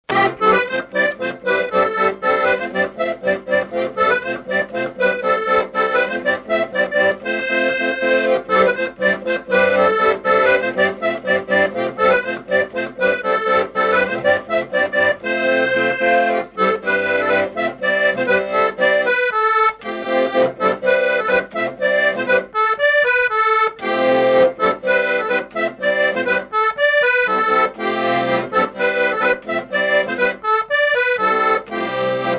marche nuptiale
Répertoire d'airs à danser
Pièce musicale inédite